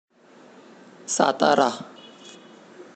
Satara_pronunciation.ogg.mp3